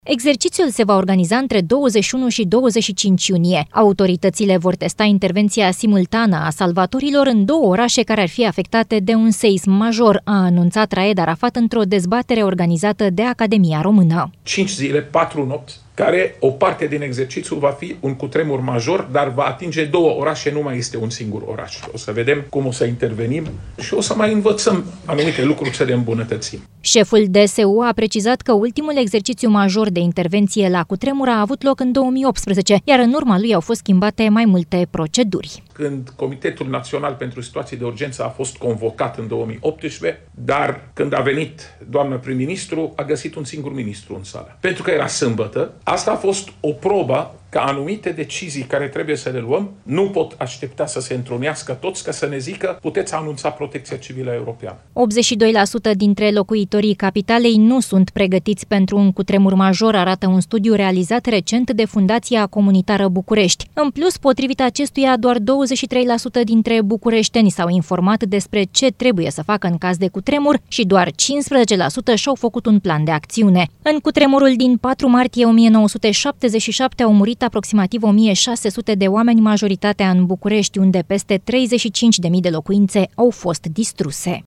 Exercițiul se va organiza între 21 și 25 iunie. Autoritățile vor testa intervenția simultană a salvatorilor în două orașe care ar fi afectate de un seism major, a anunțat Raed Arafat la o dezbatere organizată de Academia Română.
Șeful DSU, Raed Arafat: „O să vedem cum o să intervenim și o să mai învățăm anumite lucruri să le îmbunătățim”